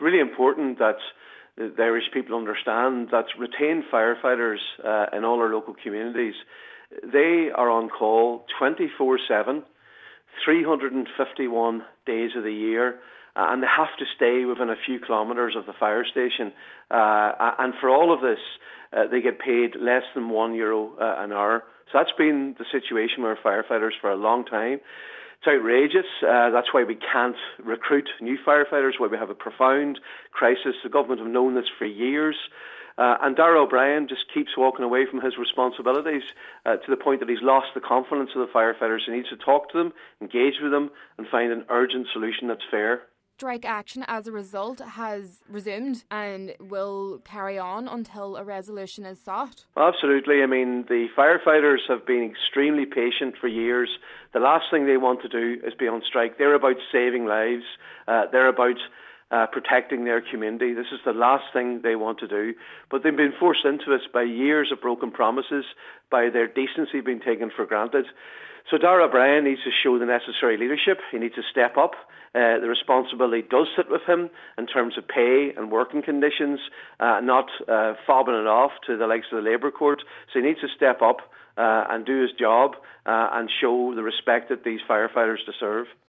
Deputy Padraig MacLochlainn says the working conditions are outrageous…….